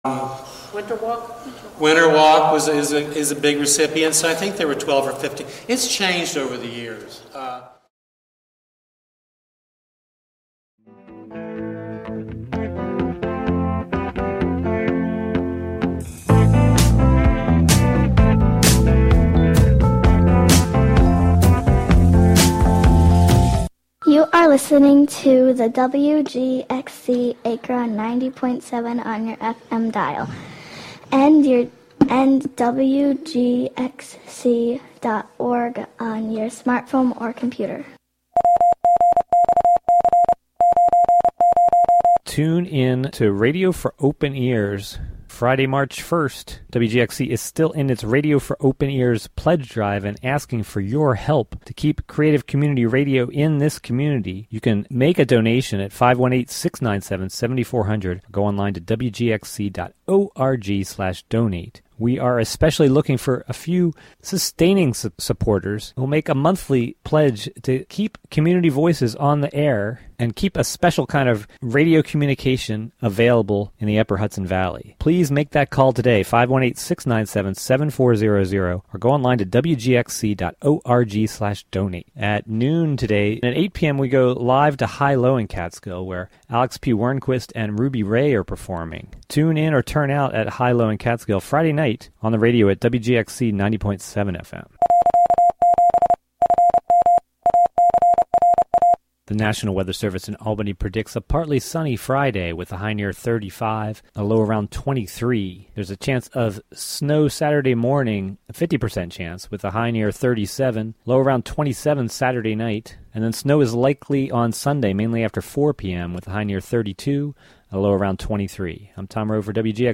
You’re invited to put on your boots and join us every Friday morning as we meander through the wild areas of our modern urban landscape, exploring contemporary and classic Americana, folk, country and elusive material that defies genre.
Broadcast live from the Hudson studio.